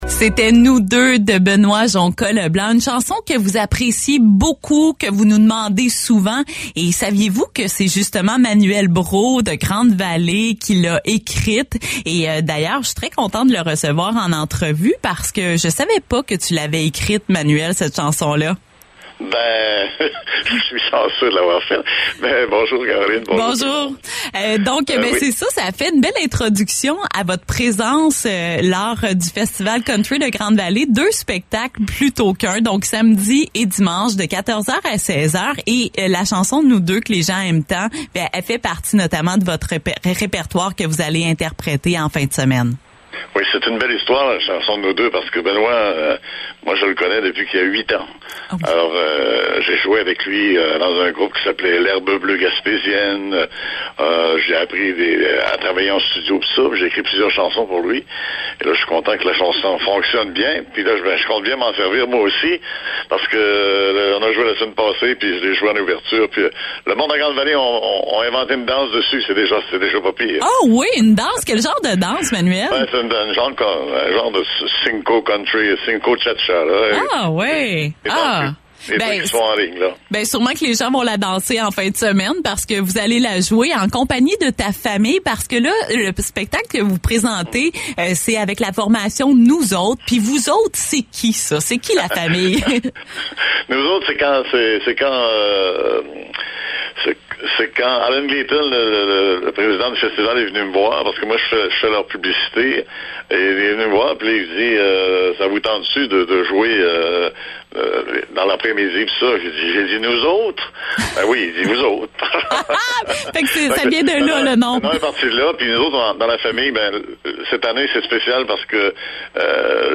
a discuté de ces belles retrouvailles familiales en compagnie du chanteur.